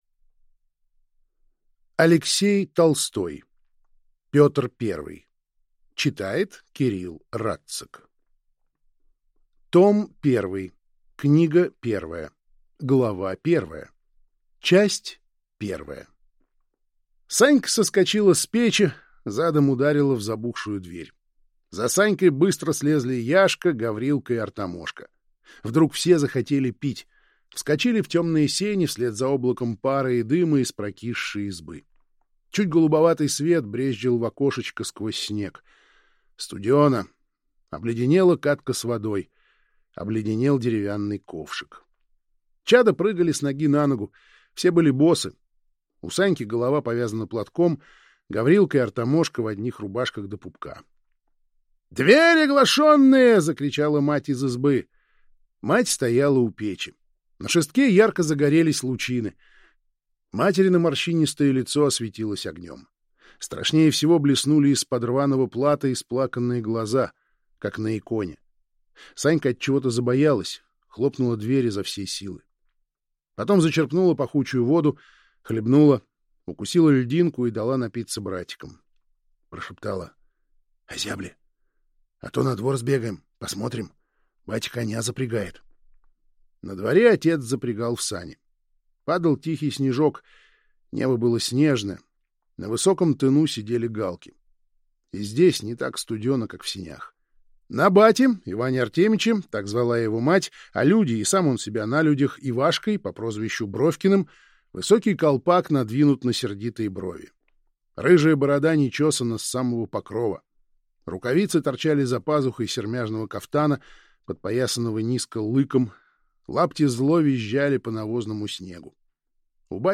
Аудиокнига Петр Первый. Том 1 | Библиотека аудиокниг